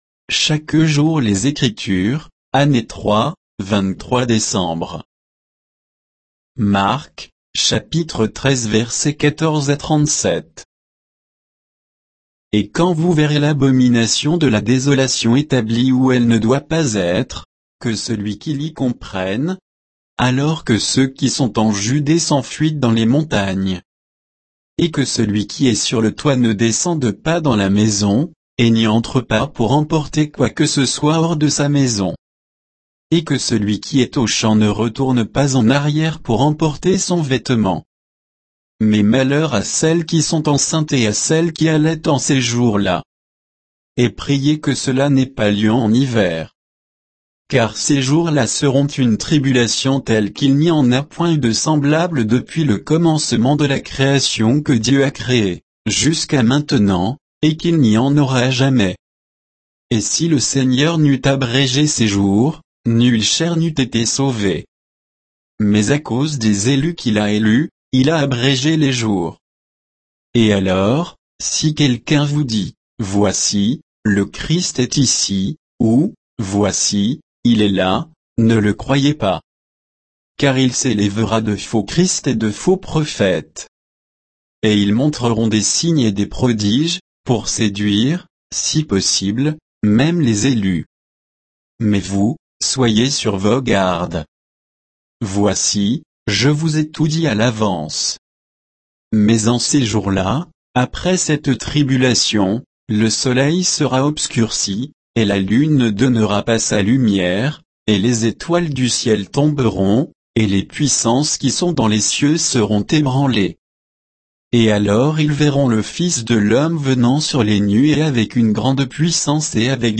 Méditation quoditienne de Chaque jour les Écritures sur Marc 13